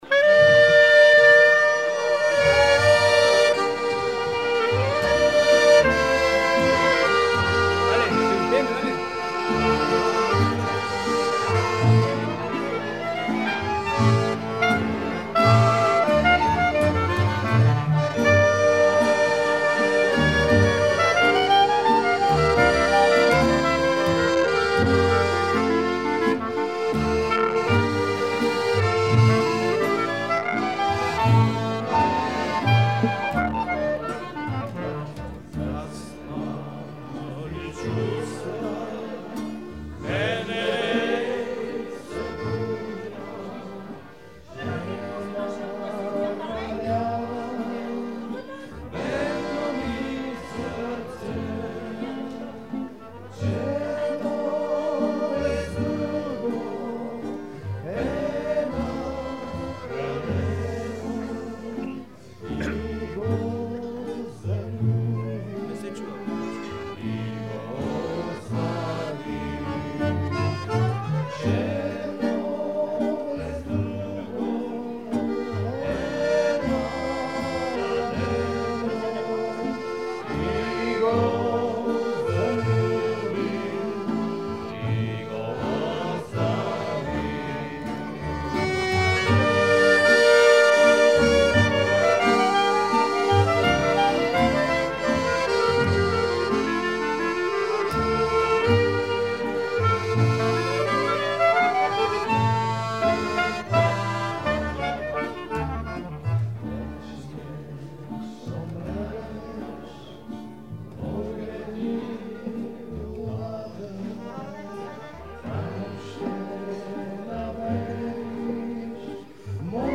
Chanson bulgare
Pièce musicale inédite